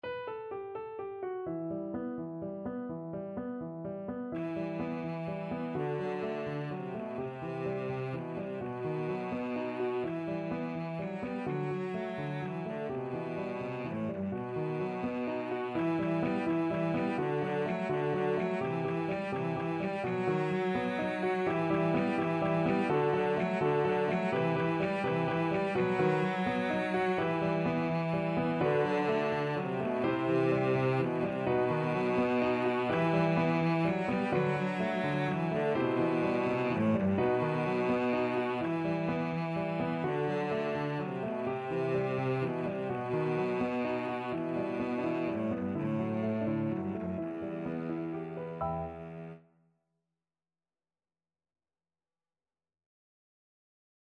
3/4 (View more 3/4 Music)
Animato . = c.84
World (View more World Cello Music)